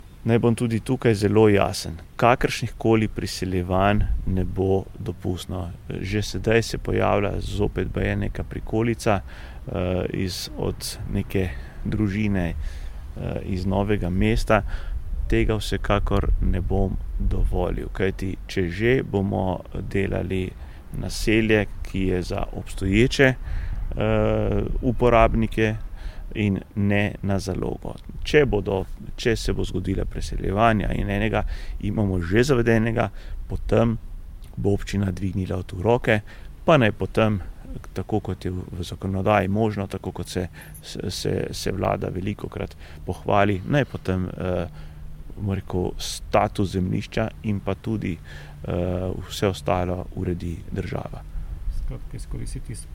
Župan Samo Pogorelc o tem, kdaj bodo v Otavicah dobili vodo